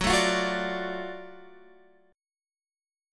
GbmM7#5 Chord